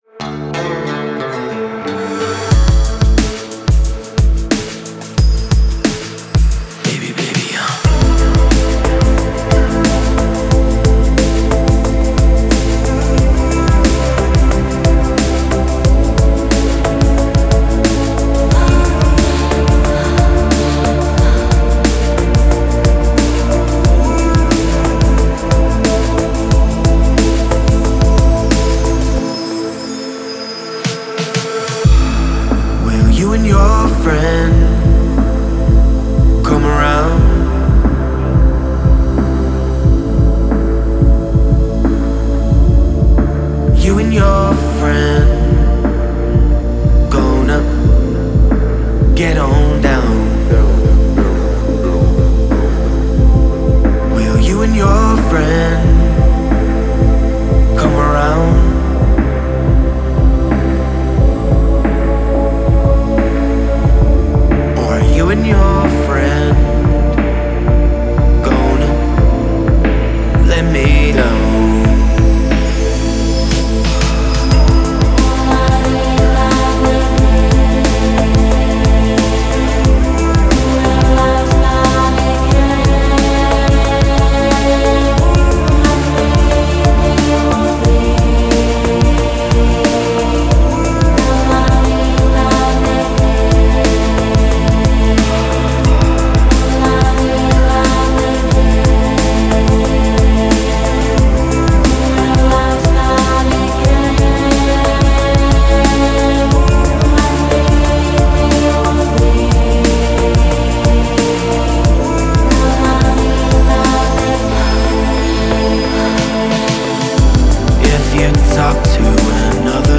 Спокойная музыка
спокойные треки